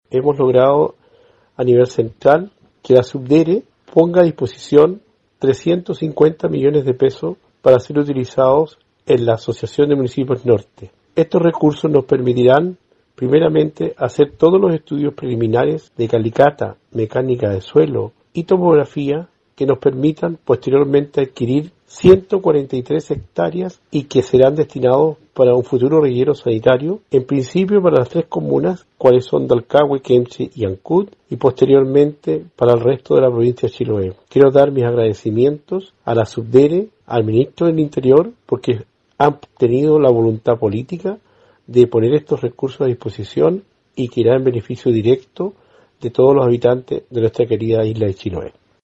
El alcalde de Dalcahue, Juan Hijerra entregó su opinión con respecto a la solución que dio la Subdere.
CUÑA-JUAN-HIJERRA-.mp3